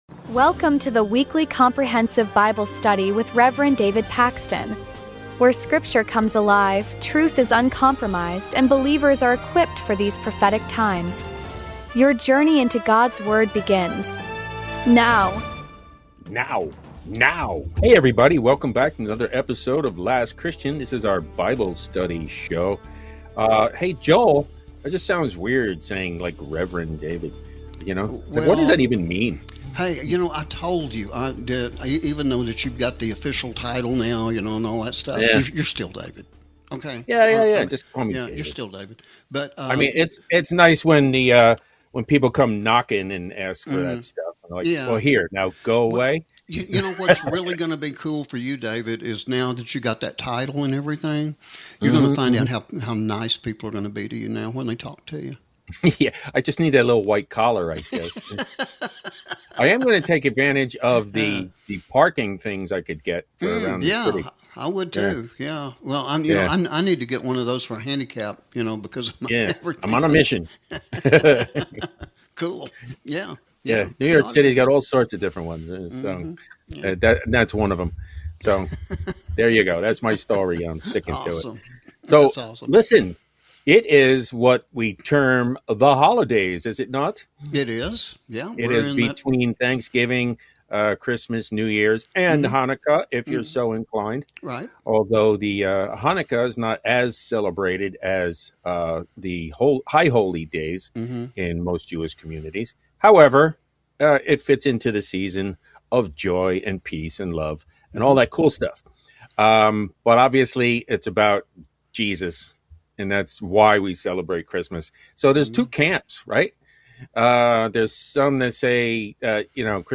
This is Bible study the way it was meant to be.